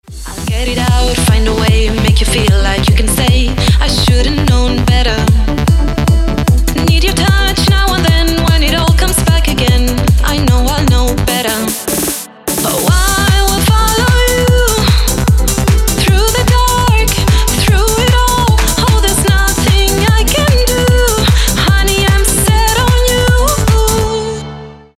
Workout Mix Edit 150 bpm